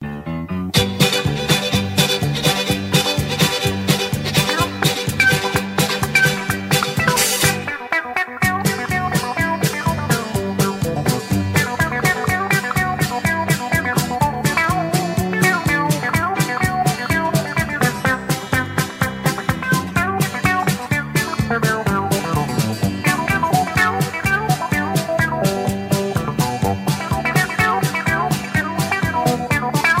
Singing Call (instrumental) + Patter